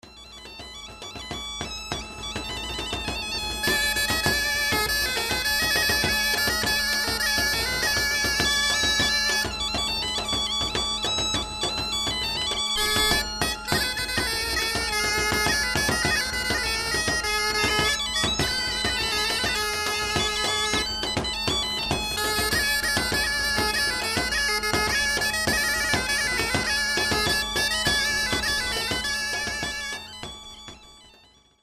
Y a pas si longtemp en Bretagne (jusqu'à 1910) la gamme non-tempéré était utilisé pour les instruments (accordéon, biniou-bombarde), de nos jours, on en revien à ces gammes non-tempéré sur certain repertoir breton (comme le vannetais), j'ai d'ailleur en ma possession un couple biniou-bombarde non tempéré...
Exemple de non-tempéré en couple biniou-bomarde: